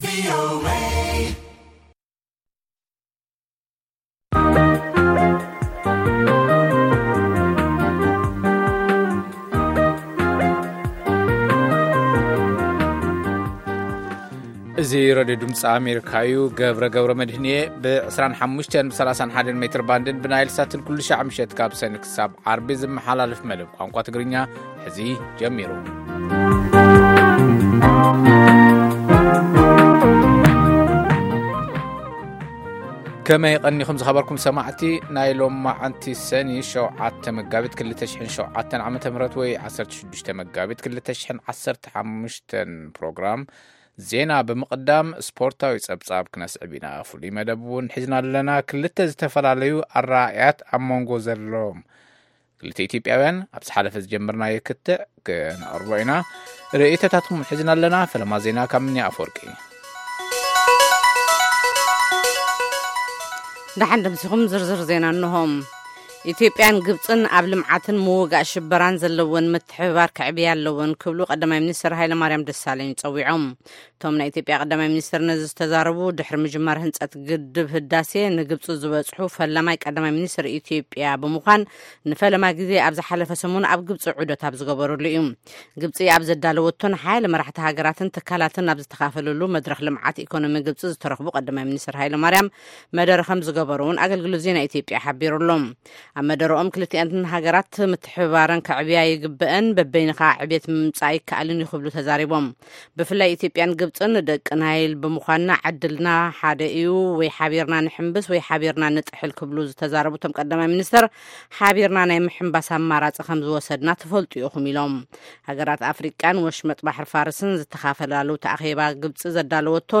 ፈነወ ትግርኛ ብናይ`ዚ መዓልቲ ዓበይቲ ዜና ይጅምር ። ካብ ኤርትራን ኢትዮጵያን ዝረኽቦም ቃለ-መጠይቓትን ሰሙናዊ መደባትን ድማ የስዕብ ። ሰሙናዊ መደባት ሰኑይ፡ ሳይንስን ተክኖሎጂን / ሕርሻ